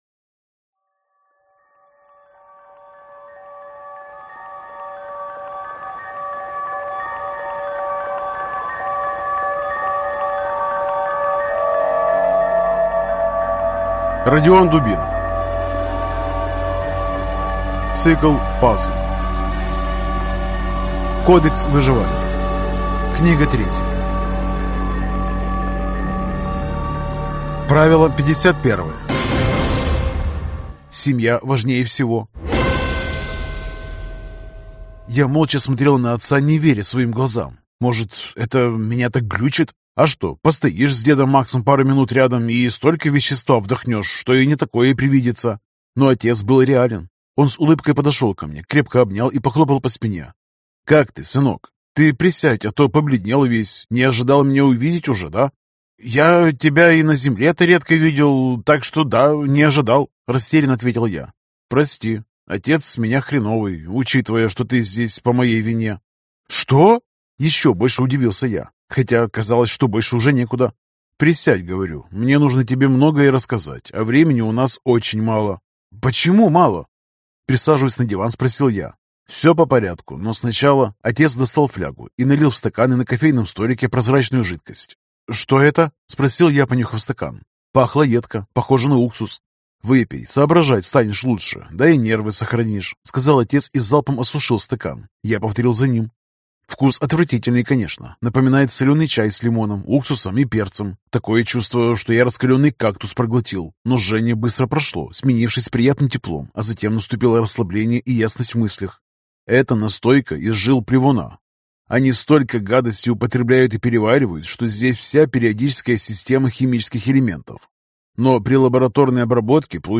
Аудиокнига Кодекс выживания (часть 3) | Библиотека аудиокниг